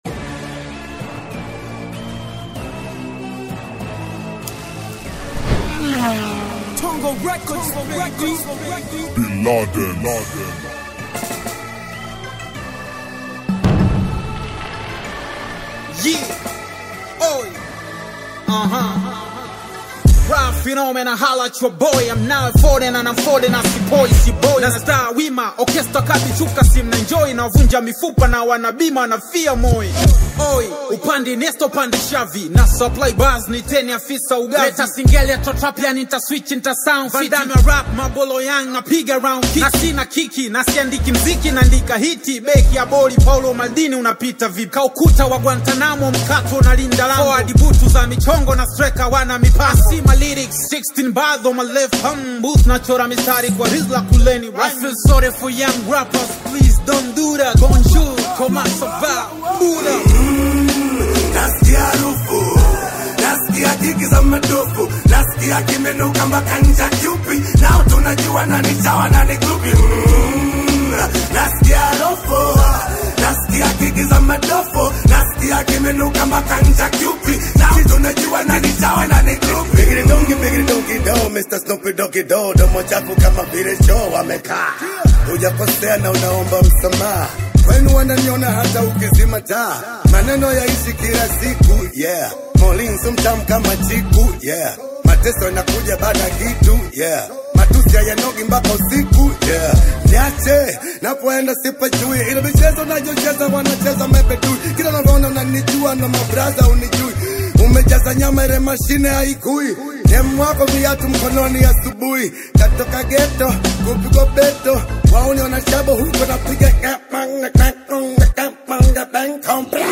conscious rap